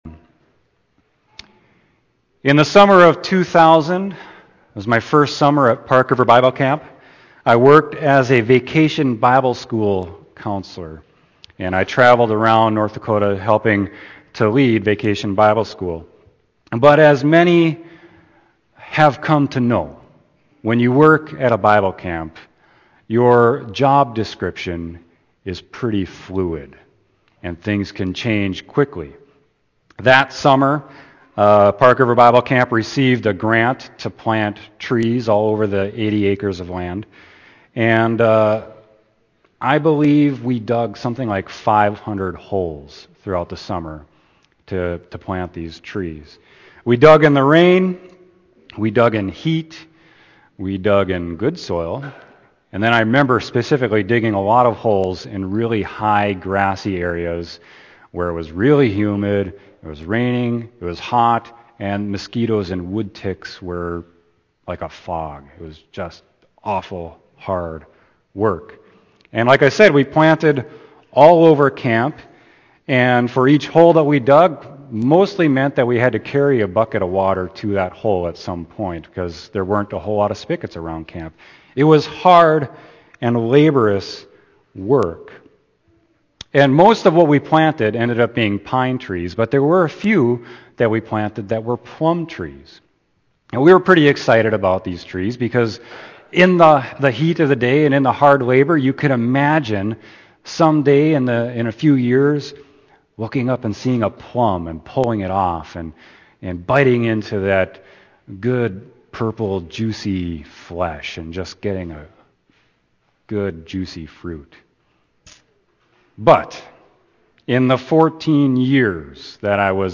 Sermon: John 15.1-8